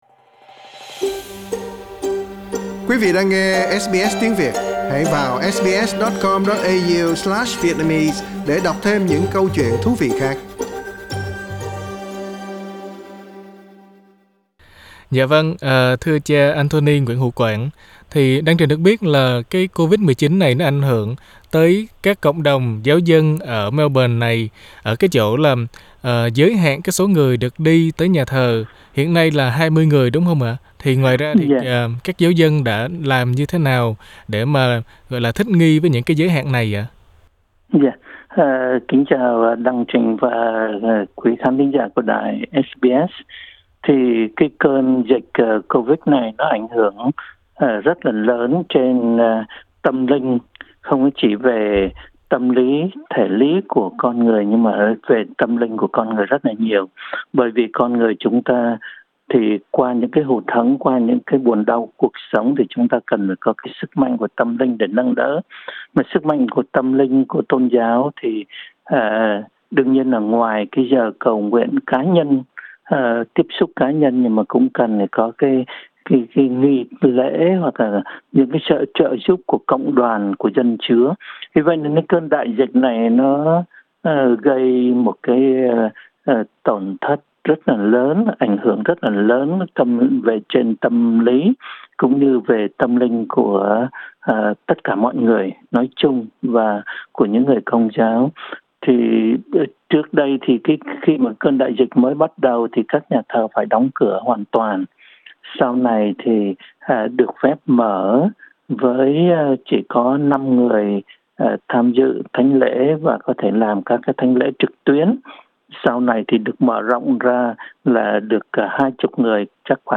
SBS phỏng vấn